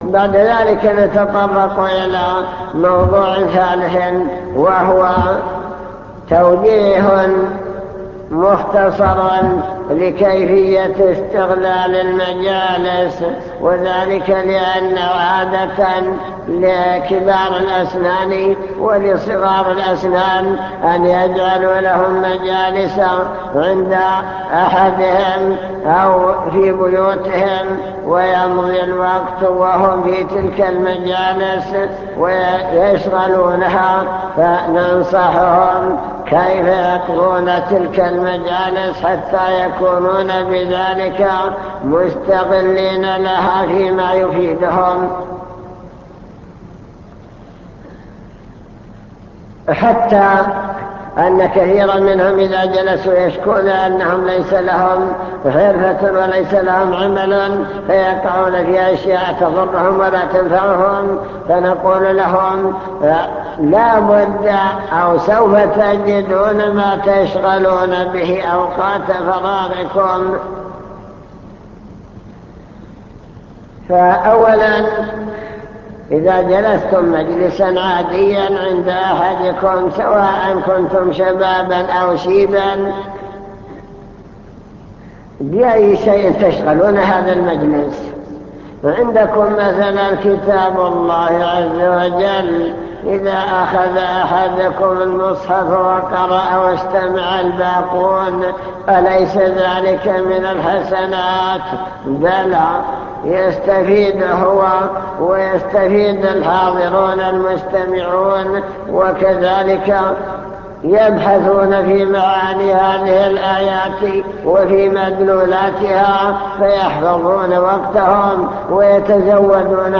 المكتبة الصوتية  تسجيلات - محاضرات ودروس  محاضرة بعنوان الشباب والفراغ